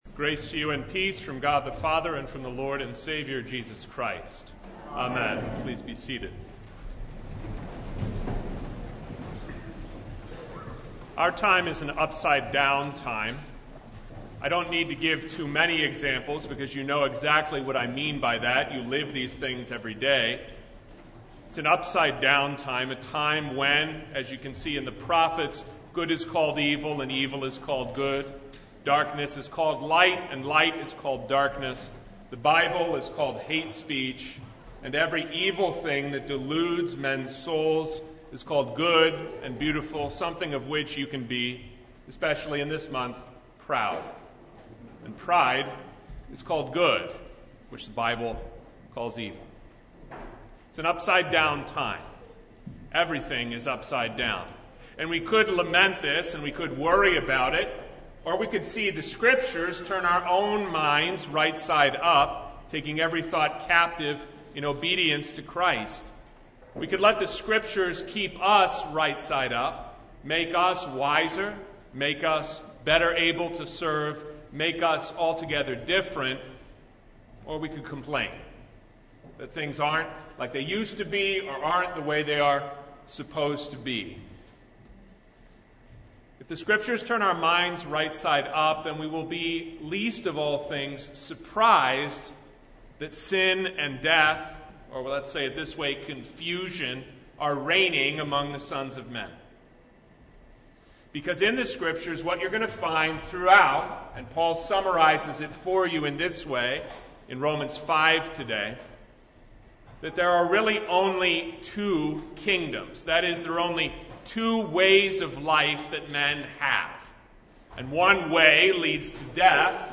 Sermon from The Second Sunday after Holy Trinity (2023)